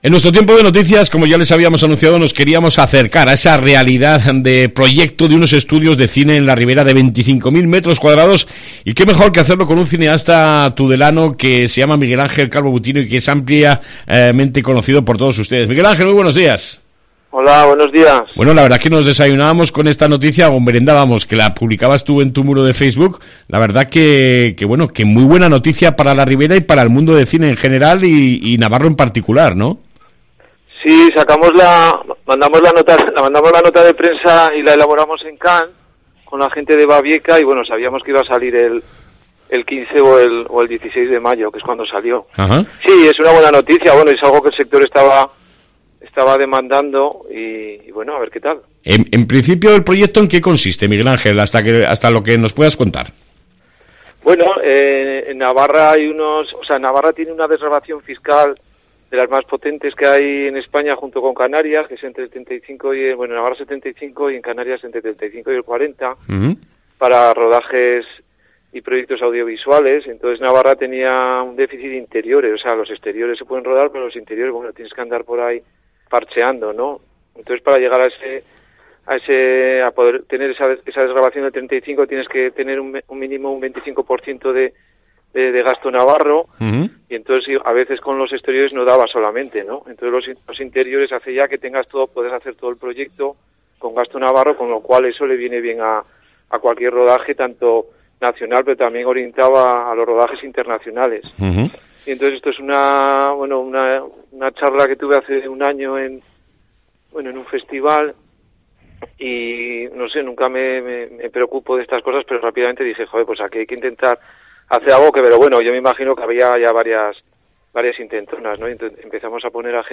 AUDIO: Entrevista con uno de los impulsores de los Nuevos estudios de Cine que vienen a la Ribera